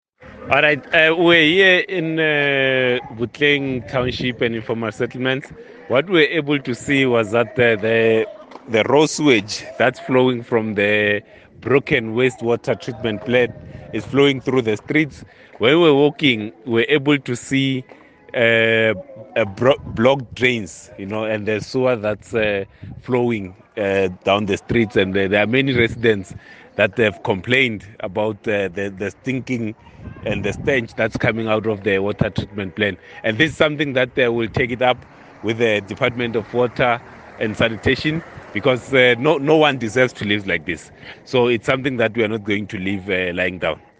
voiceclip from DA Team One South Africa Spokesperson on Basic Services, Makashule Gana